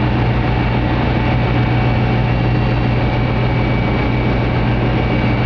ec135_rotor_in.wav